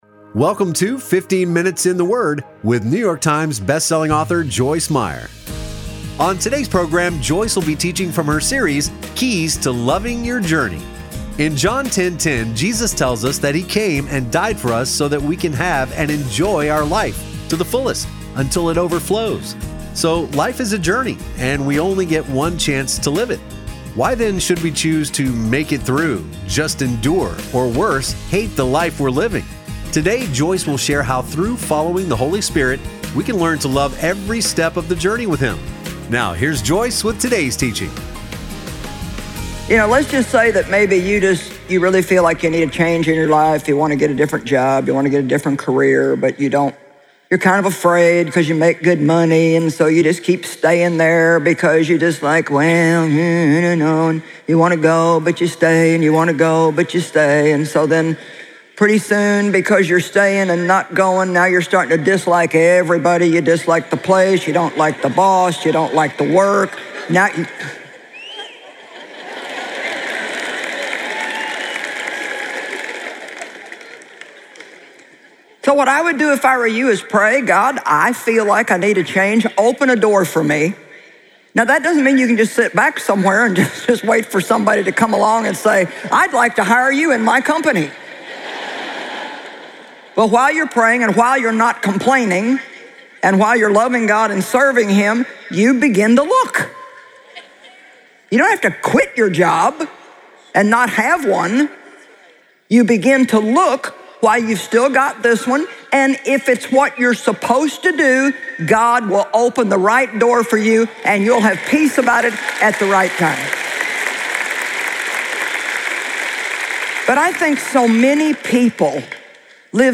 Joyce Meyer teaches on a number of topics with a particular focus on the mind, mouth, moods and attitudes. Her candid communication style allows her to share openly and practically about her experiences so others can apply what she has learned to their lives.